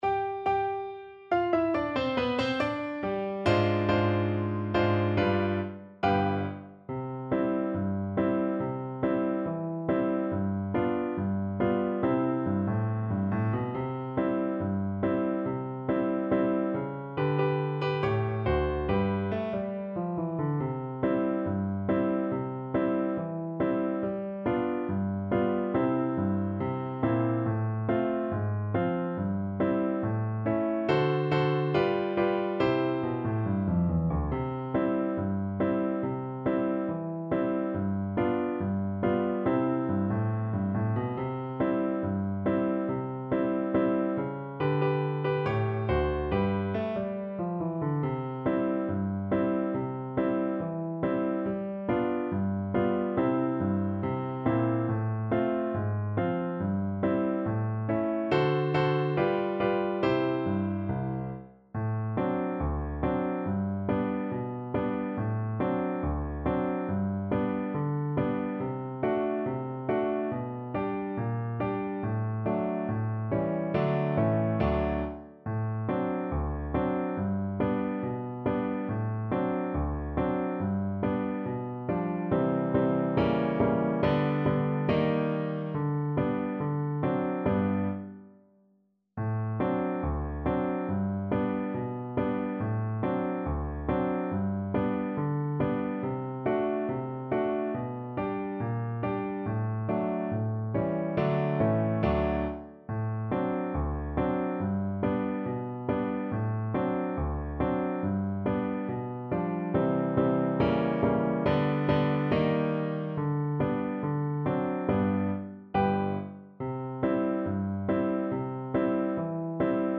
C major (Sounding Pitch) G major (French Horn in F) (View more C major Music for French Horn )
2/4 (View more 2/4 Music)
Not fast Not fast. = 70